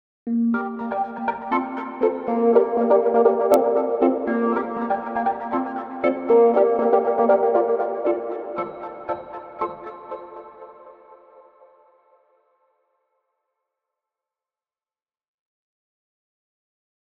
Tipo: sound_effect